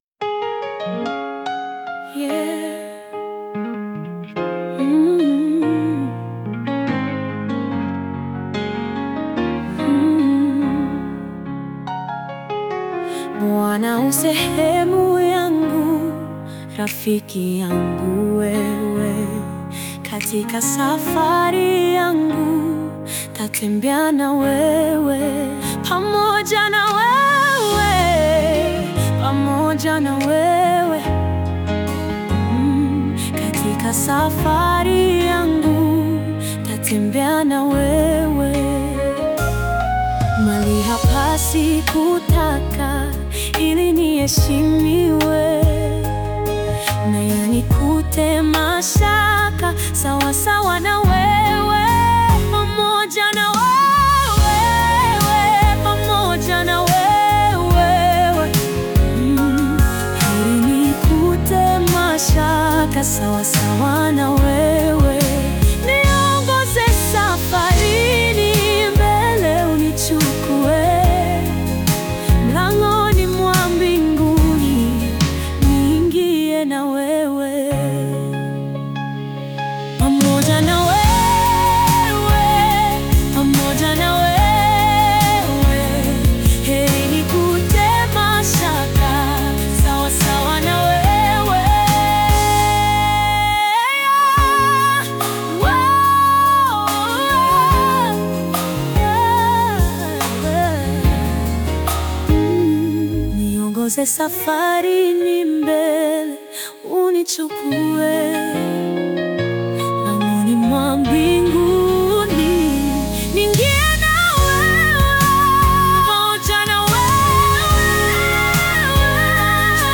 Juzi sasa nilitengeneza wimbo kwa kutumia AI, dakika 3 wimbo ukakamilika na kuwa mzuri sana, una mixing na mastering ya hali ya juu sana. Muimbaji ni wa viwango vya kutisha. Na nilichofanya ni kuandika tu nataka wimbo uimbwe na mwanamke na mahadhi ni soul, na vikawa.